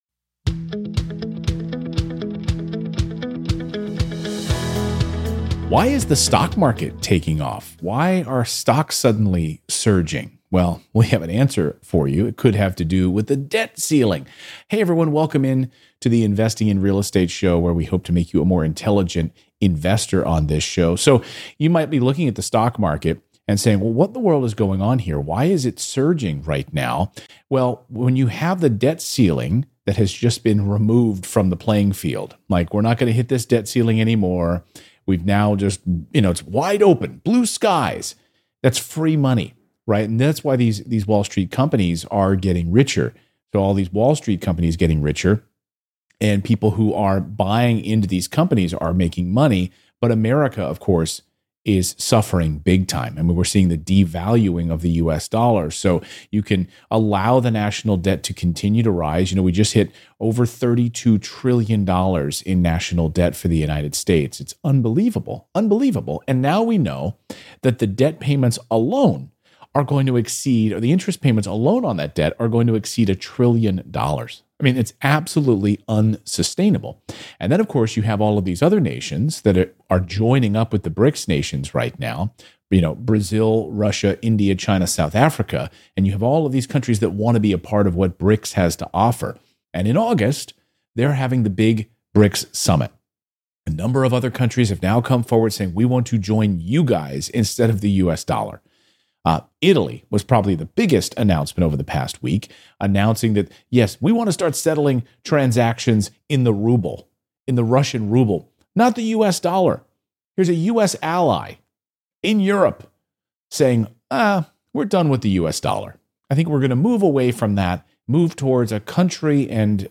Today's first caller wants to set up a self-directed IRA to buy real estate. What's the difference between Roth and traditional IRAs?